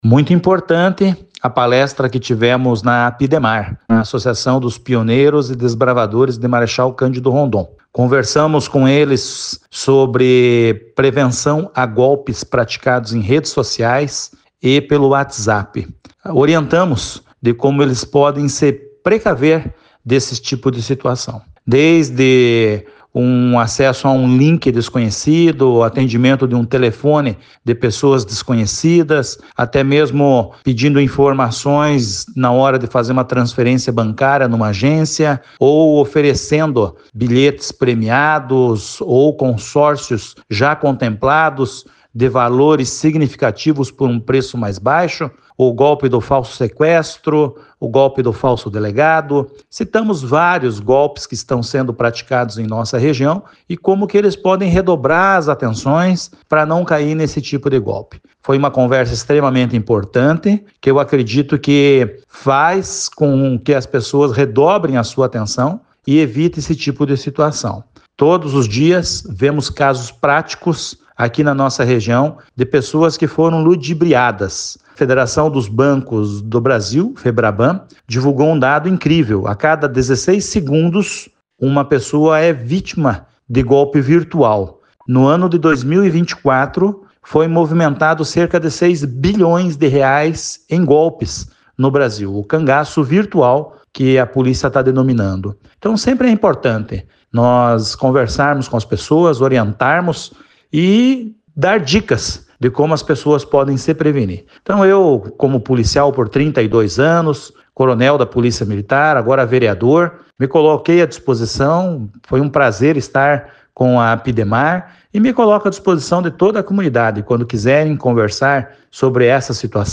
Vereador coronel Welyngton palestra sobre golpes para integrantes da Associação Dos Deficientes
Ontem, o vereador coronel Welyngton Alves da Rosa, participou do primeiro encontro anual da Associação dos Pioneiros e Desbravadores de Marechal Cândido Rondon.